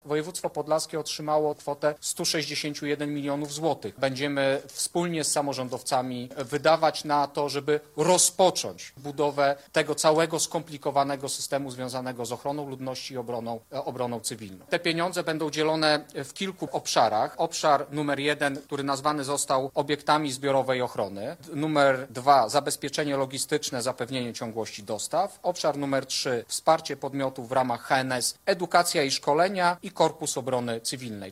Pieniądze zostaną wykorzystane w kilku obszarach – o czym mówi wojewoda podlaski Jacek Brzozowski.